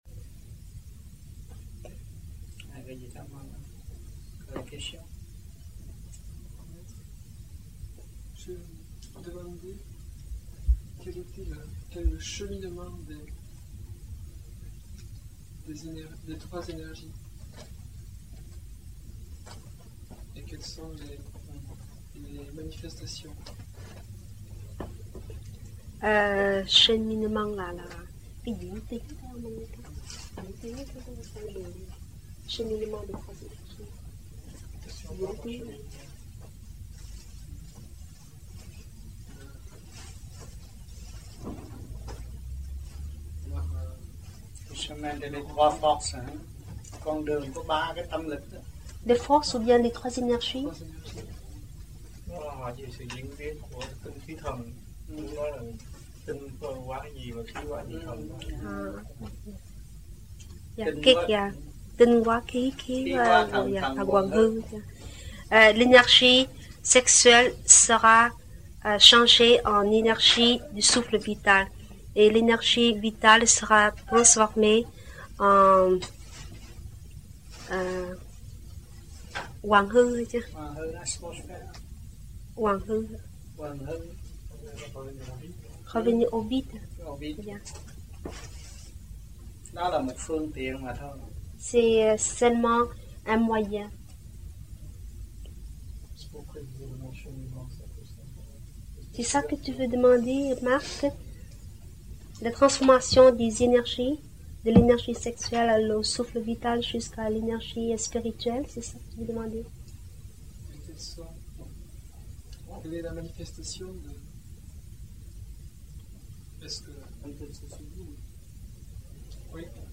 1982-12-16 - TOULOUSE - THUYẾT PHÁP 5